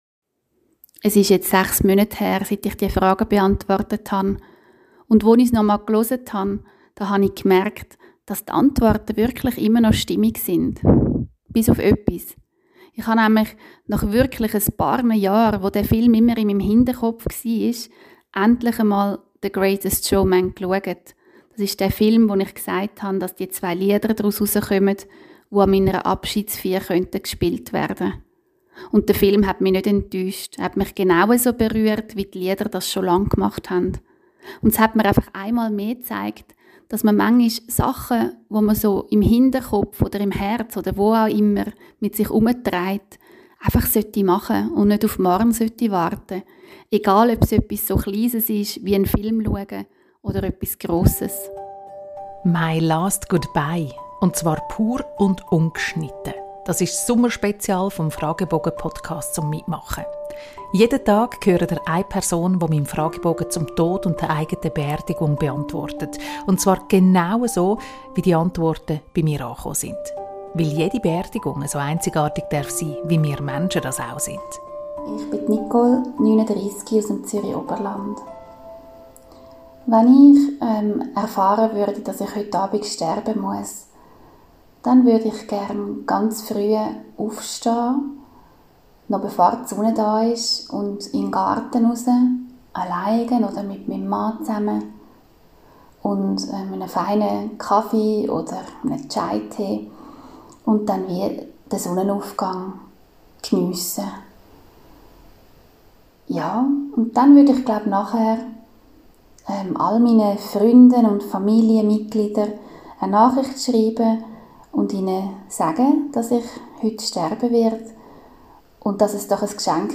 Ihr hört die Antworten auf meinen MY LAST GOODBYE-Fragebogen genau so, wie sie via Whatsapp-Sprachmessage bei mir gelandet sind.